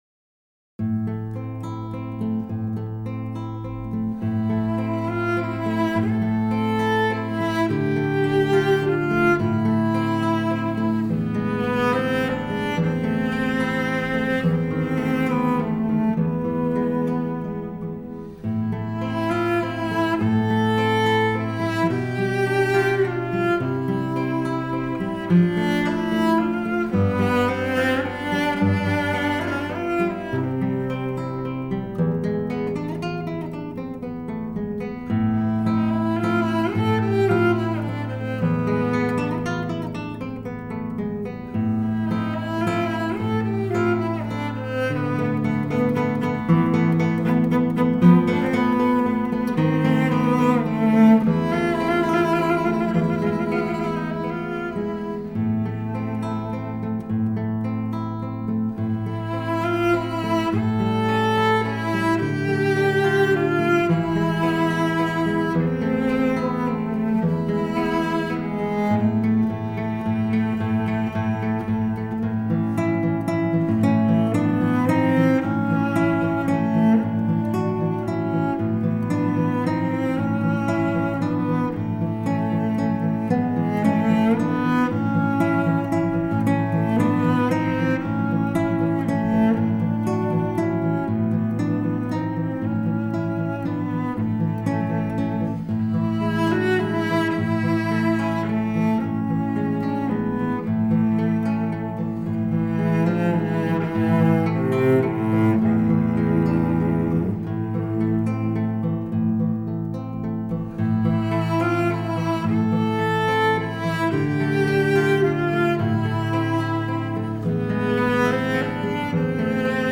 大提琴.吉他